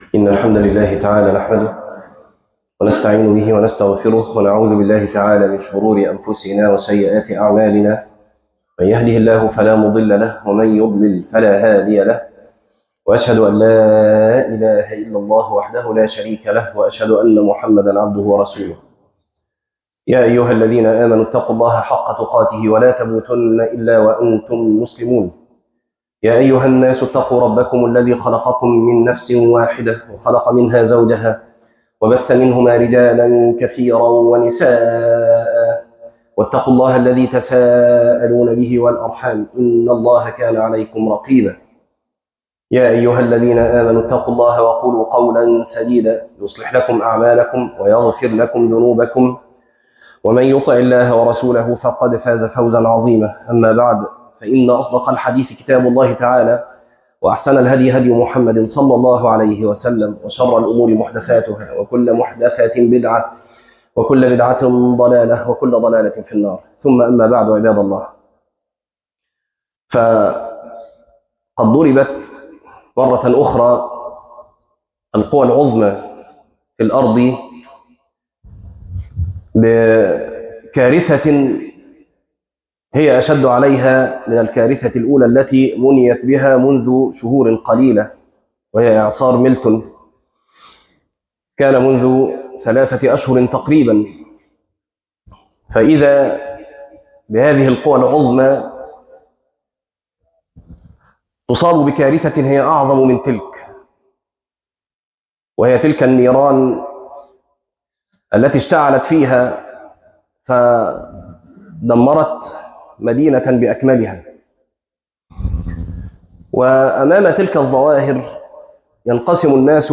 تفاصيل المادة عنوان المادة حرائق كاليفورنيا - خطبة تاريخ التحميل الأثنين 13 ابريل 2026 مـ حجم المادة 9.96 ميجا بايت عدد الزيارات 1 زيارة عدد مرات الحفظ 0 مرة إستماع المادة حفظ المادة اضف تعليقك أرسل لصديق